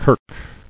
[kirk]